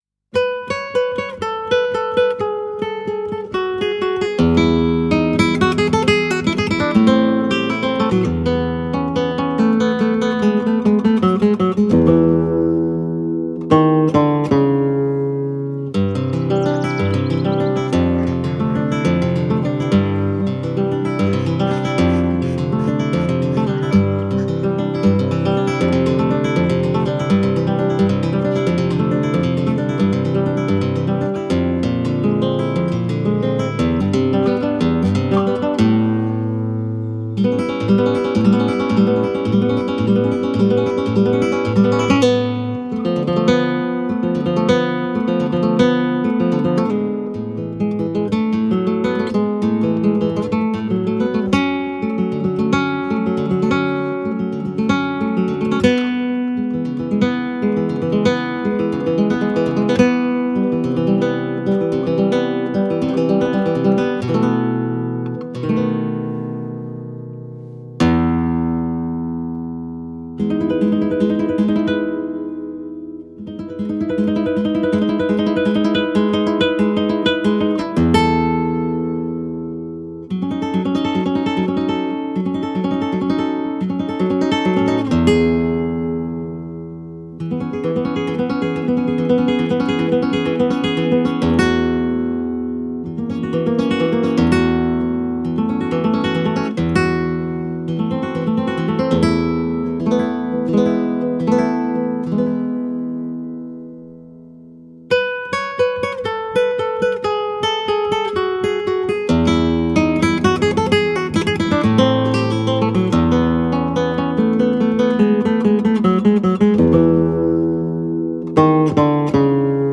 et impressionnisme
composition et guitare